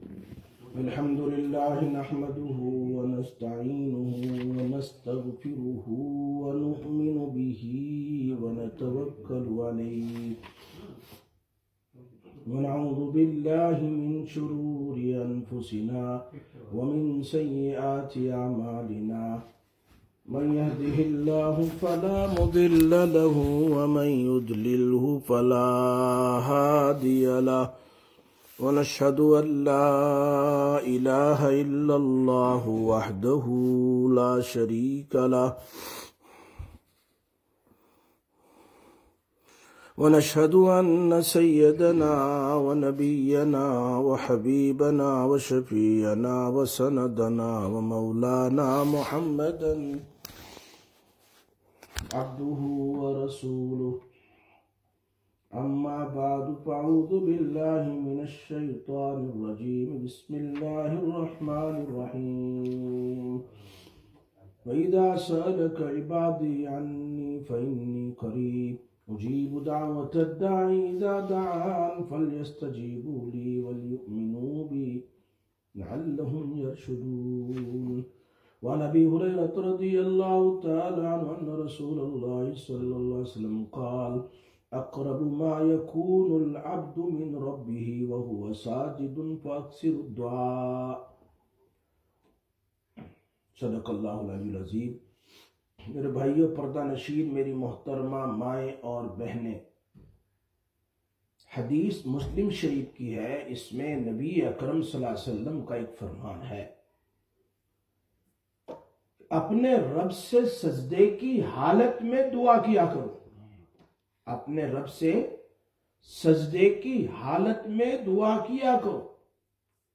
03/12/2025 Sisters Bayan, Masjid Quba